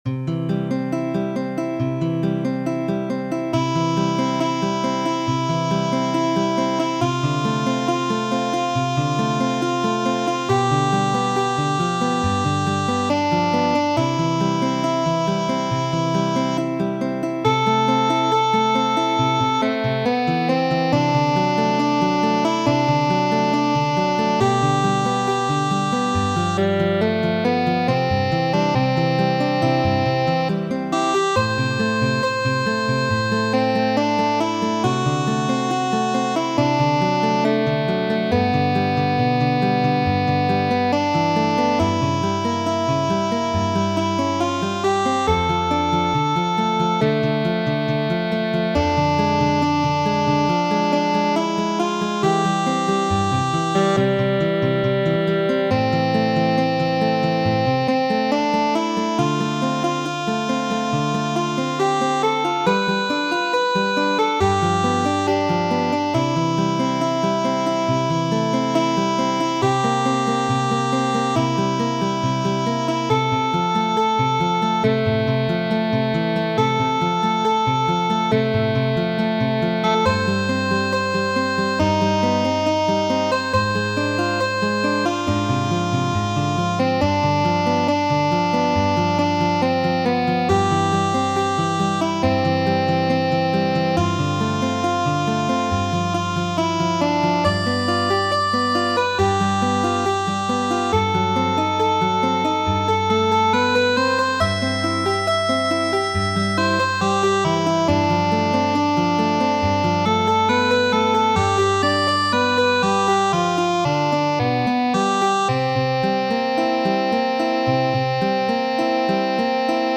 Kajeroj ~ Kajero 60 ~ PDF (24ª paĝo) Muziko: Saluton, Maria! , aranĝita de Karlo Gunod' pri preludo de Johano Sebastiano Baĥ kaj versiita por harmoniko kaj gitaro de mi mem.